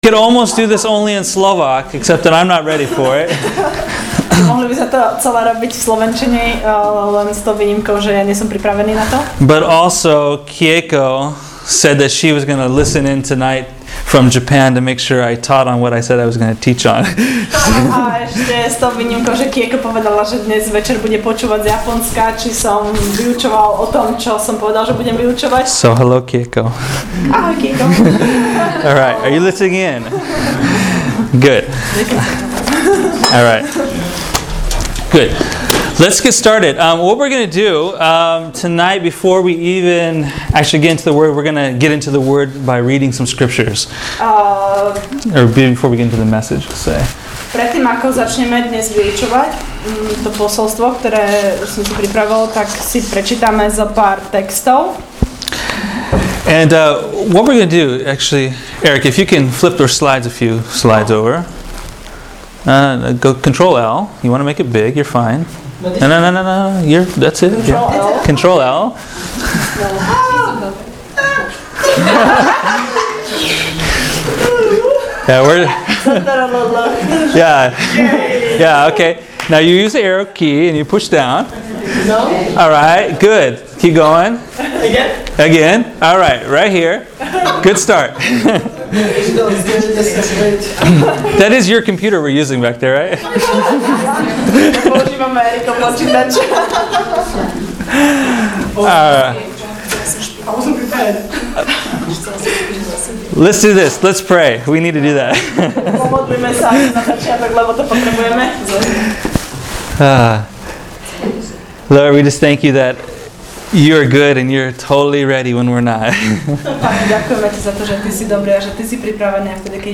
Jesus came to light the way HOME – that place special prepared for you, that sweet spot that fits you perfectly. Listen to more on this teaching from John 14:1-4 entitled: “Lighting the Way”